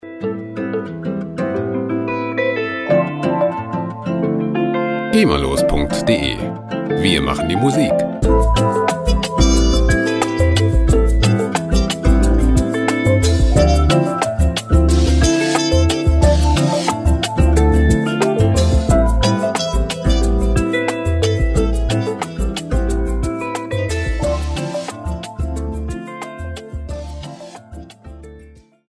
lizenzfreie Latin Musik
Musikstil: Latin Chill
Tempo: 90 bpm